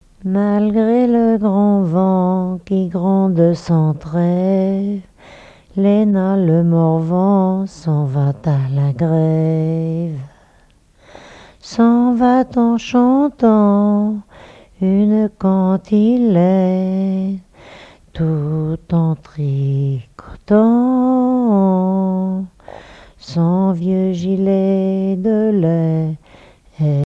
Genre strophique
collecte en Vendée
répertoire de chansons de noces
Catégorie Pièce musicale inédite